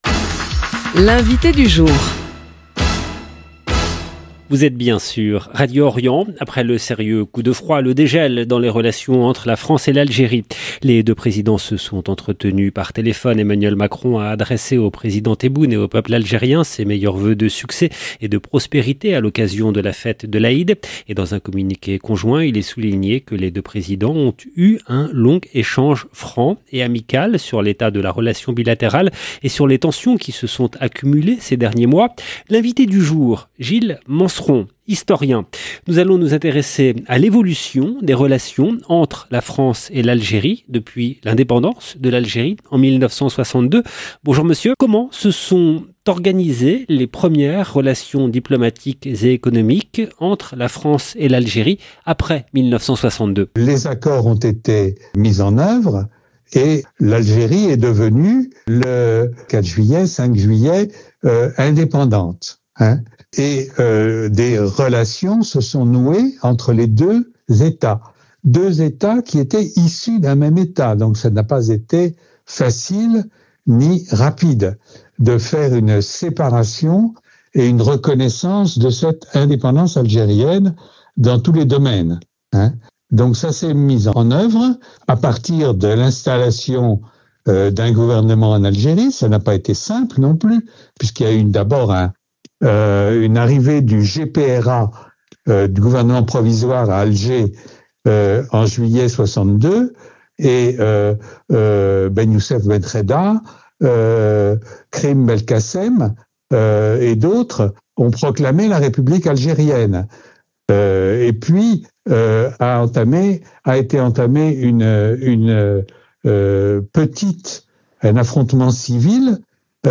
L’invité du jour